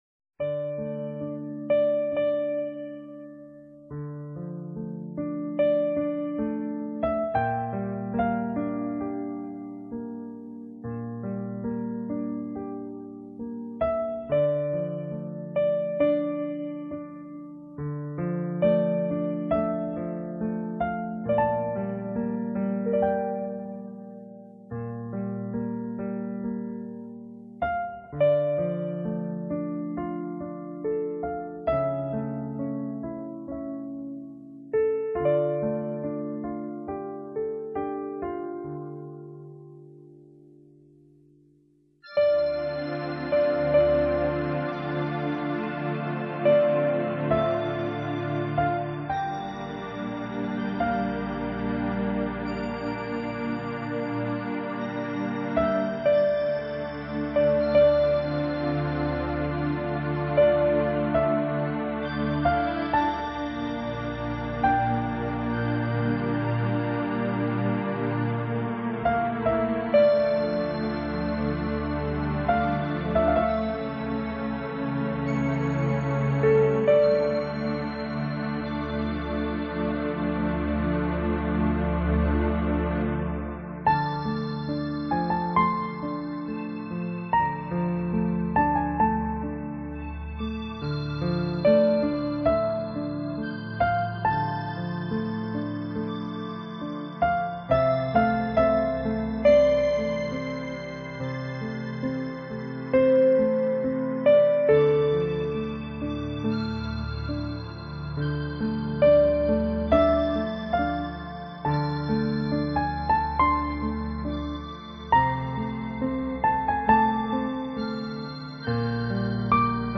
洗浴松弛音乐